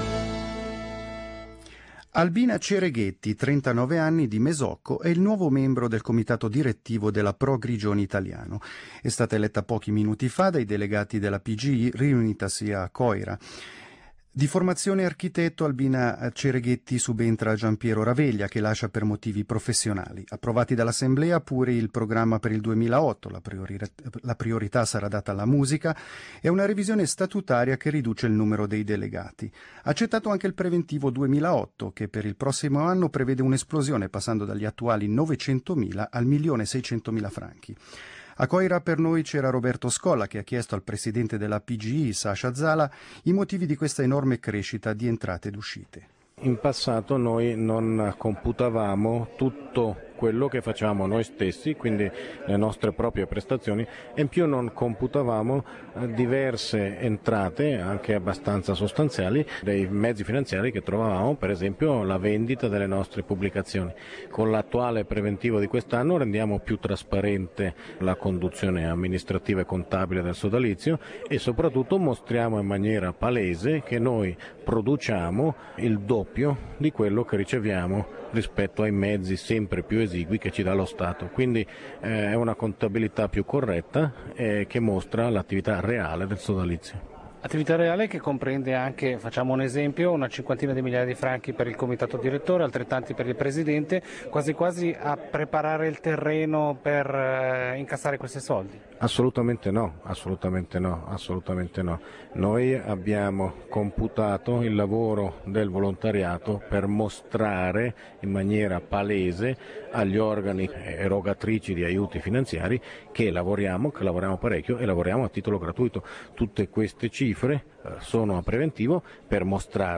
Cronache della Svizzera italiana, Intervista